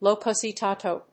発音記号
• / lóʊkoʊsɑɪtéɪṭoʊ(米国英語)
• / lˈɔkəʊsɪtάːtəʊ(英国英語)